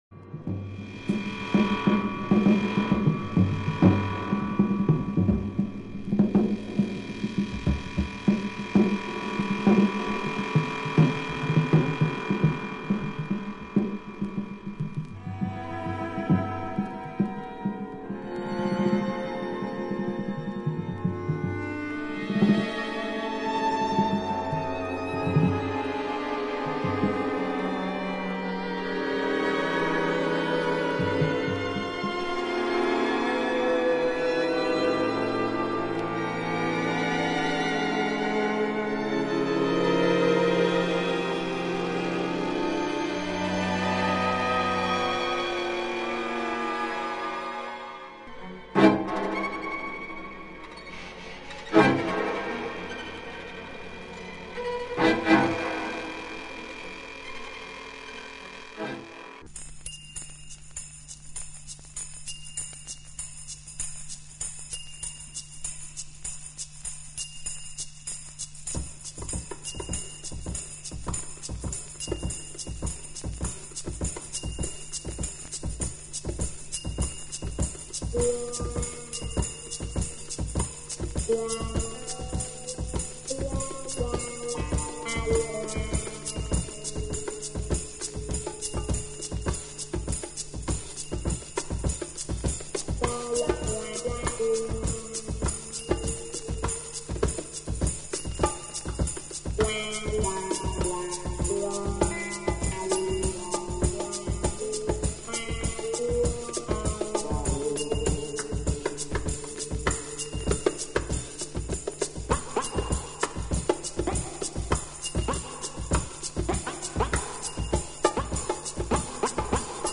crazy drumbreak with strings stabs and weird sounds